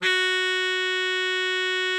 bari_sax_066.wav